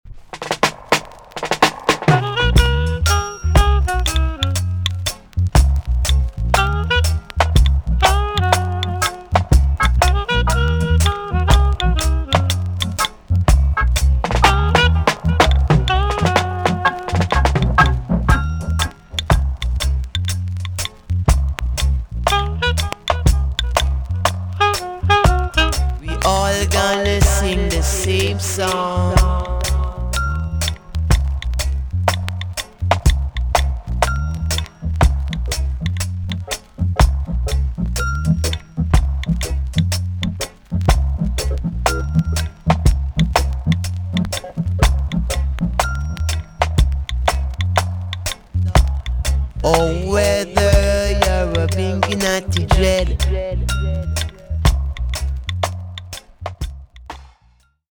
TOP >REGGAE & ROOTS
EX- 音はキレイです。